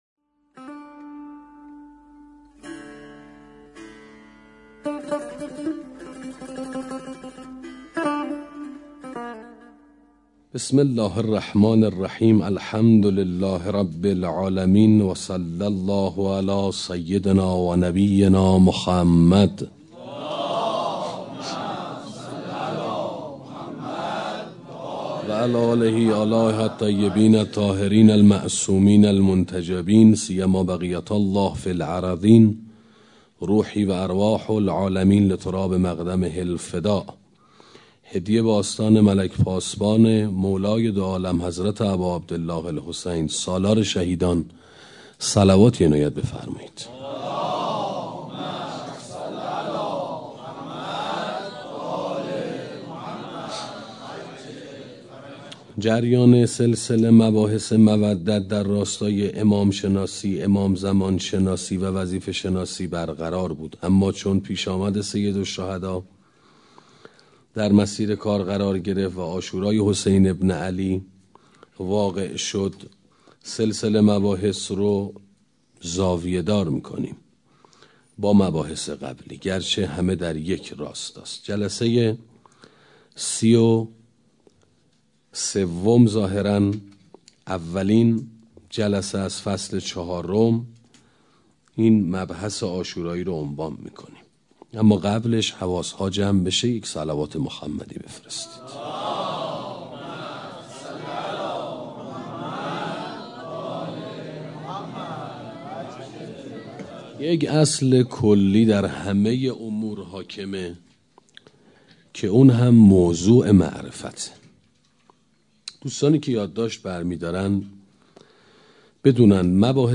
سخنرانی معرفت حسینی 1